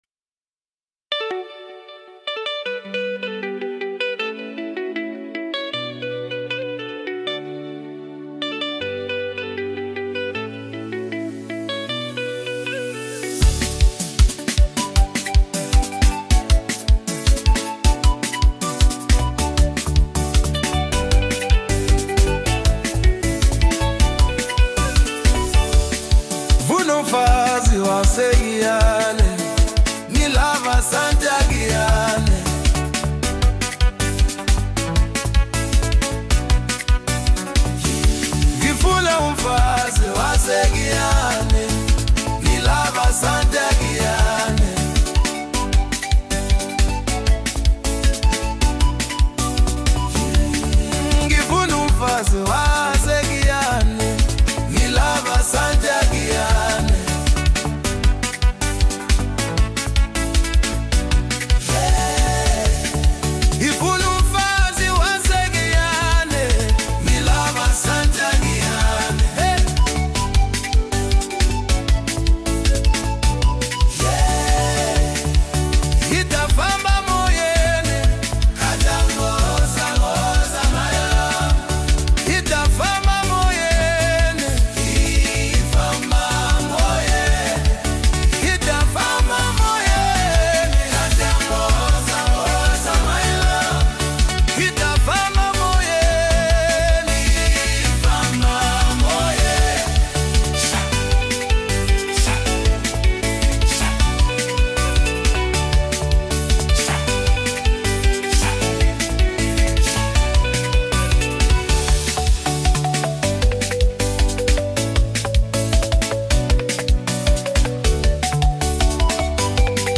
Home » Deep House » Gqom » Hip Hop
is a groovy anthem
is a track that blends rhythm and melody flawlessly